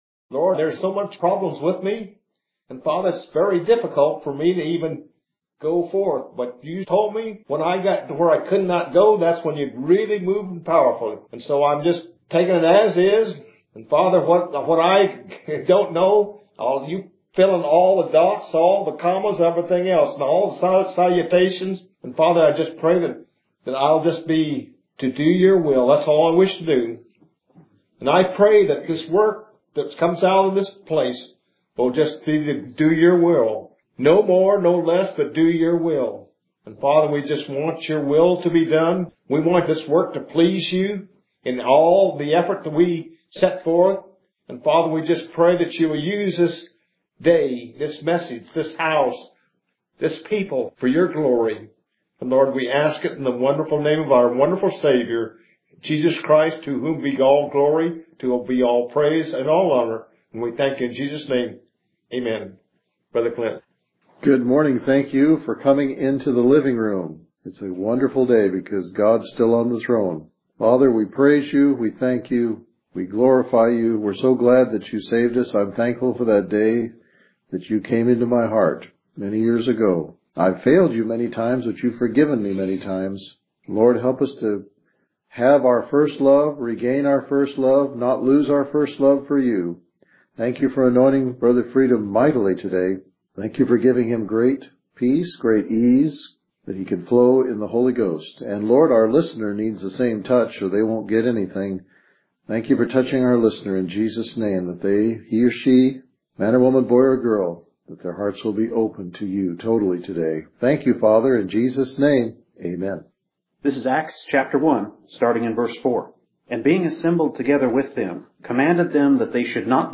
Scripture reading: Acts: 1:4-8; Acts 2:1-4,38; John 14:12-18,26